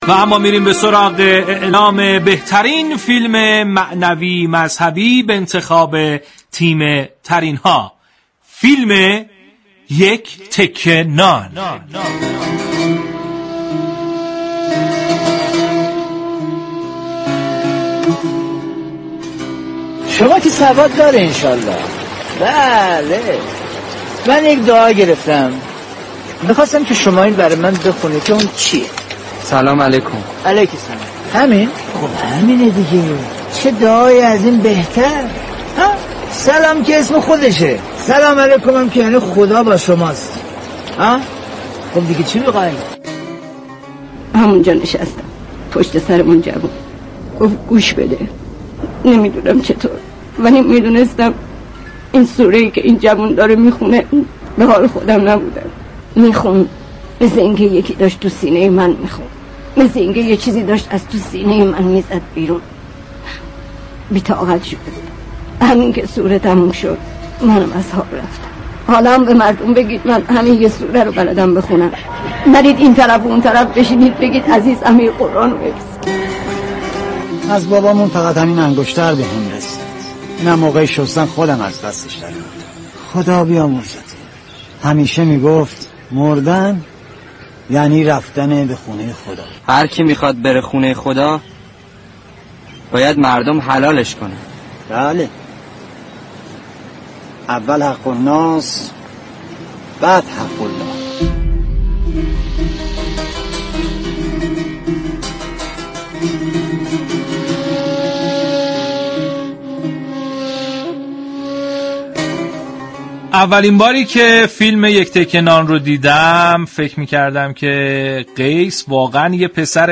این برنامه در فضایی شاد و پرانرژی تقدیم مخاطبان می شود .